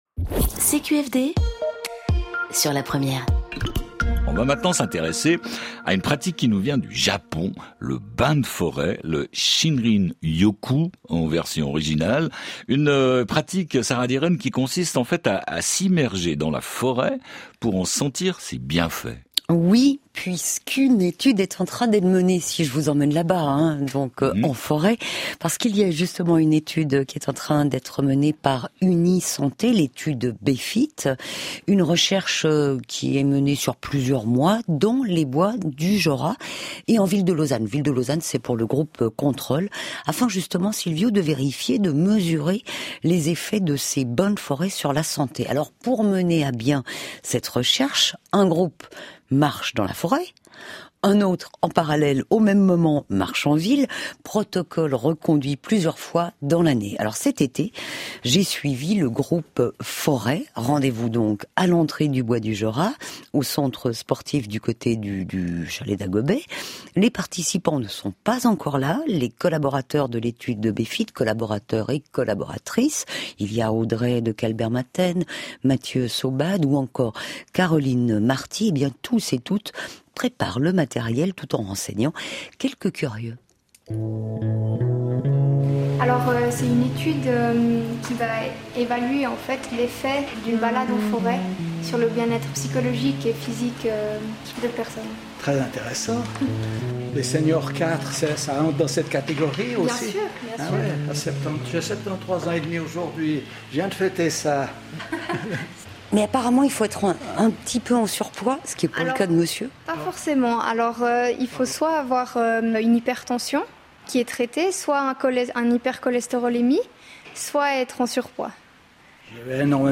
Les effets de la forêt sur la santé physique - Interview RTS
Les effets de la forêt sur la santé : Unisanté mène une recherche pour les étudier. J'ai eu l'occasion de m'exprimer dans une interview.